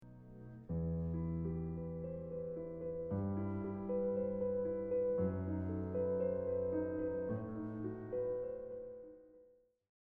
The scenery changes, to something much more frightful and threatening.
Two, because the theme/melody is the four long tones in the left hand.
2-3-1bassline.mp3